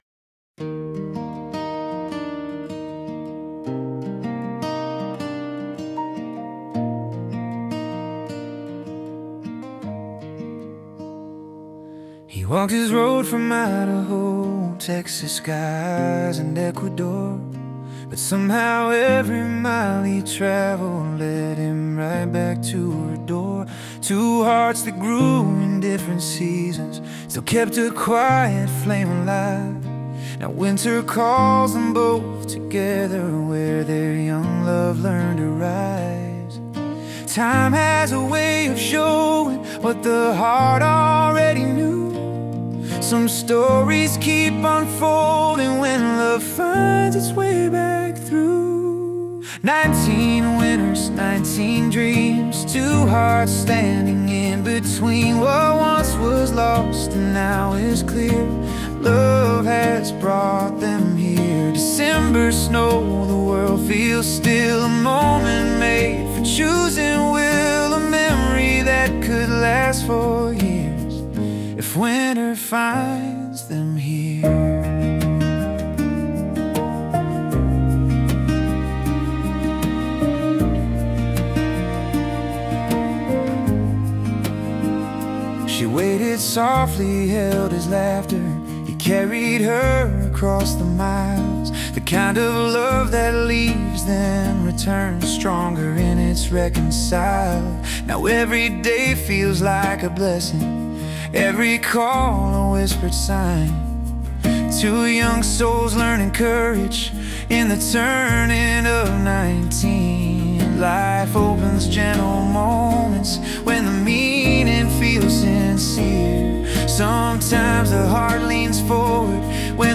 Discover the Heartbeat of Acoustic Storytelling